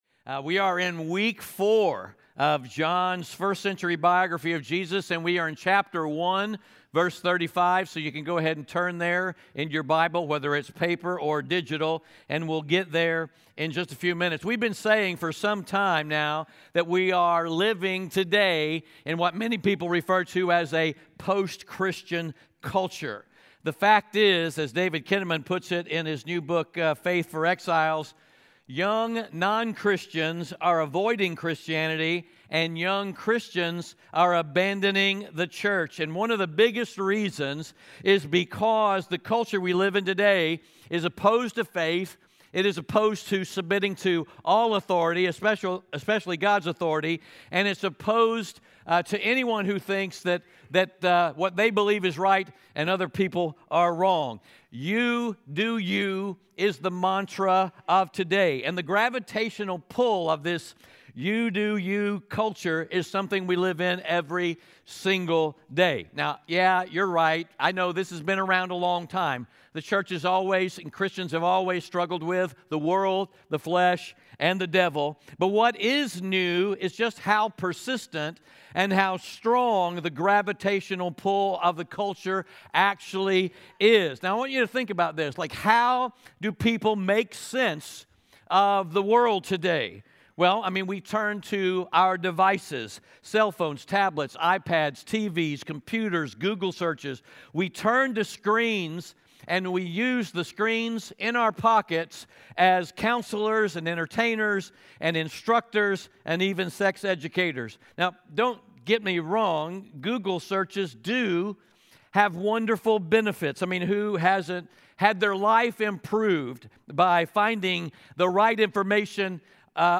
John 1:35-54 Audio Sermon Notes (PDF) Ask a Question Scripture: John 1:35-54 It’s a fact that we all have to face—every single one of us is being “discipled” by the digital Babylon we live in today.